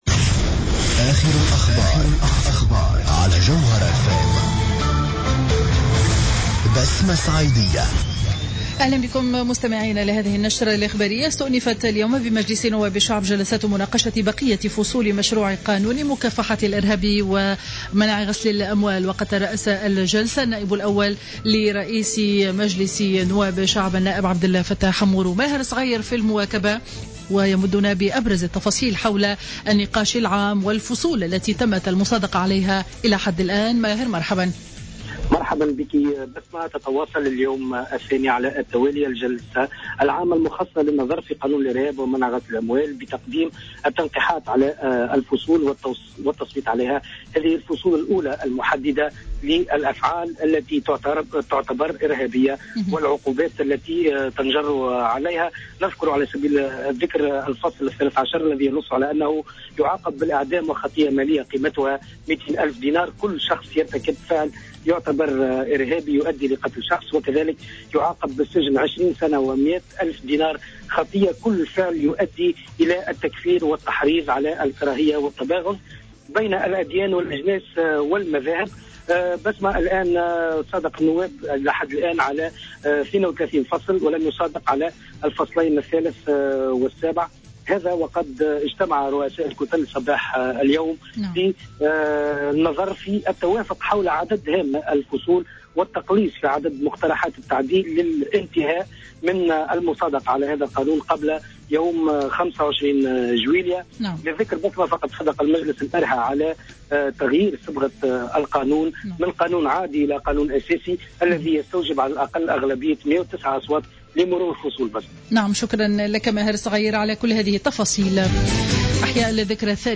نشرة أخبار منتصف النهار ليوم الخميس 23 جويلية 2015